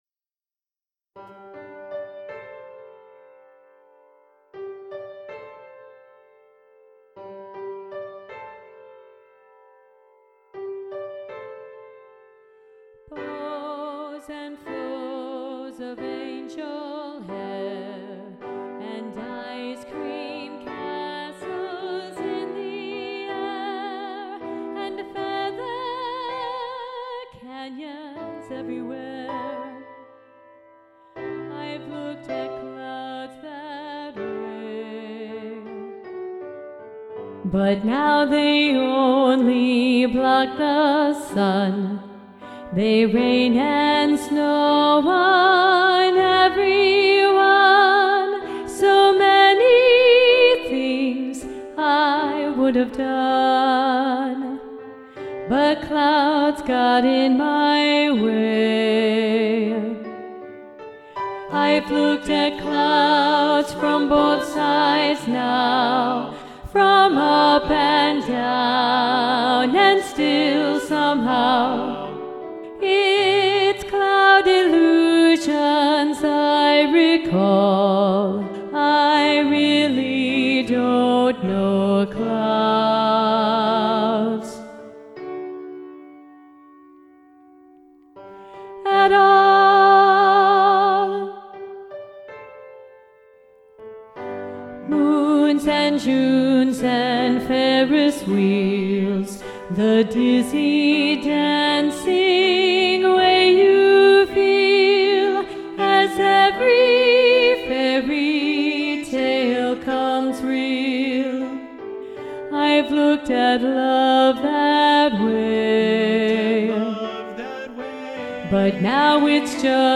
Alto 1 Predominant
Both-Sides-Now-SATB-Alto-1-Predominant-arr.-Roger-Emerson.mp3